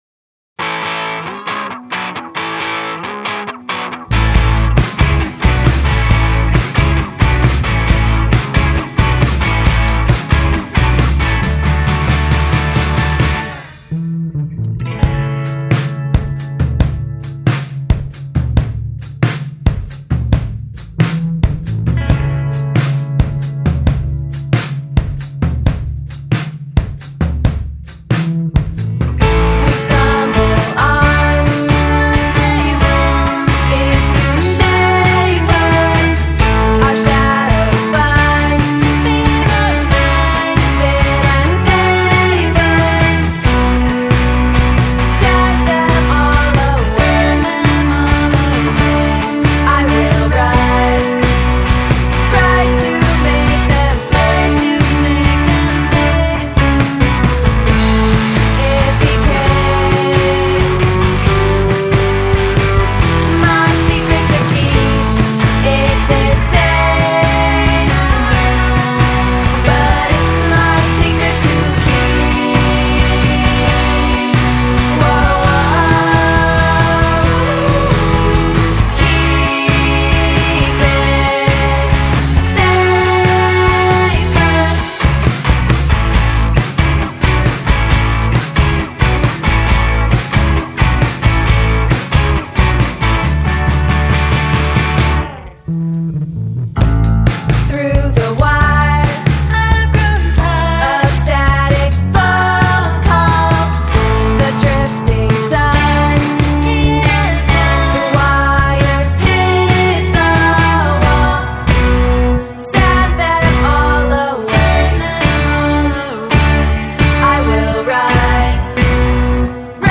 all girl soft-garage-rock